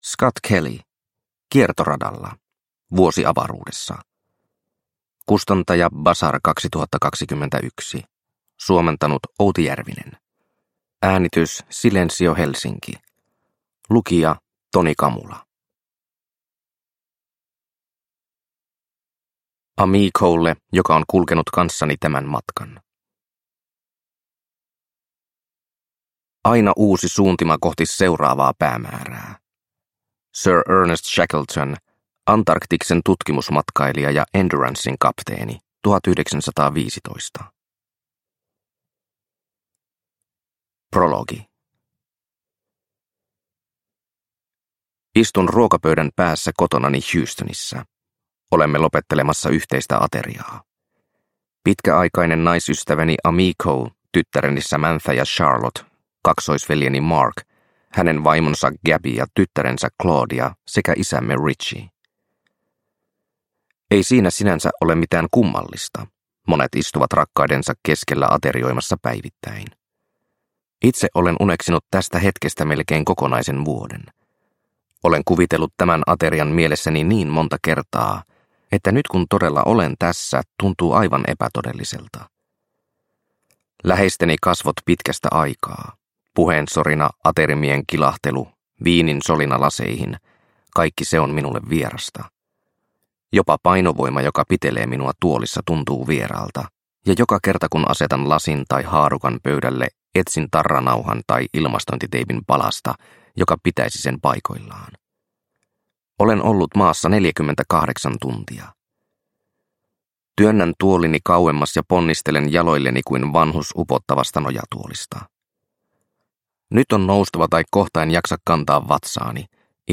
Kiertoradalla – Ljudbok – Laddas ner